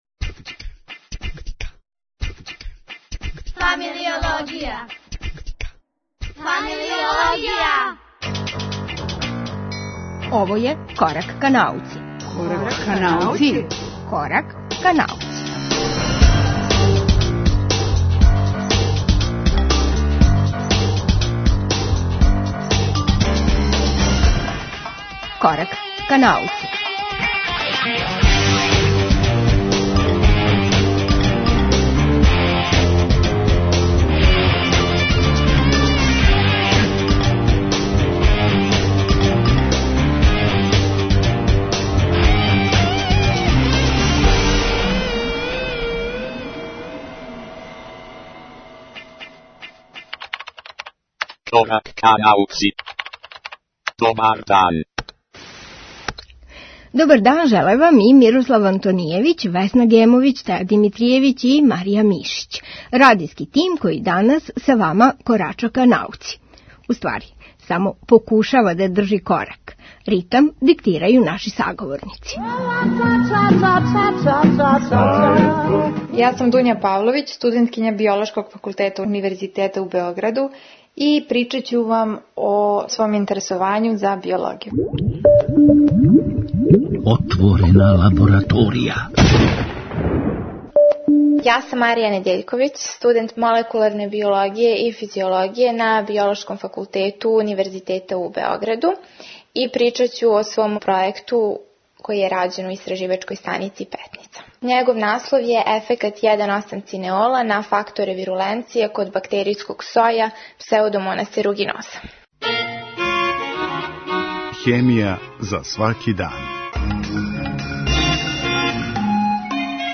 Ову емисију Корак ка науци чине четири разговора, Шест немогућих ствари пре ручка и један предах у Слободној зони.